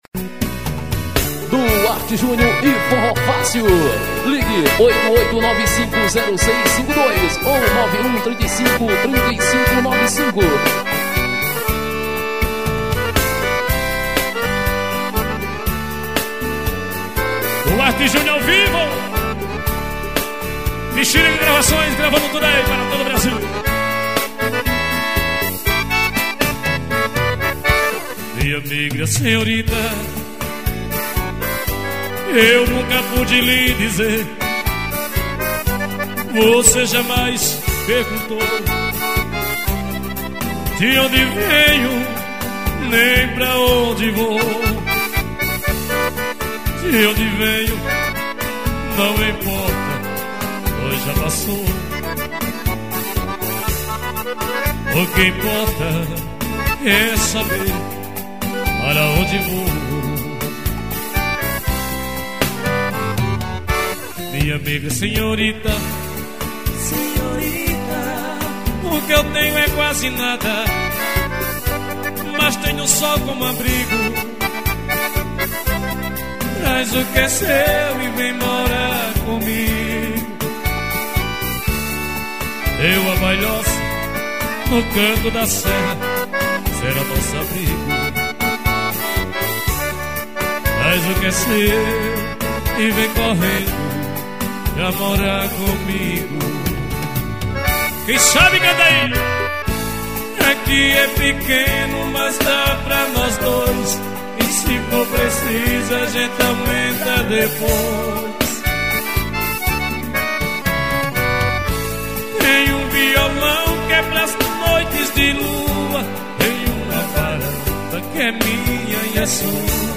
gravação de cd ao vivo.